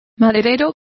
Complete with pronunciation of the translation of lumbers.